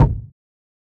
Kick (6).wav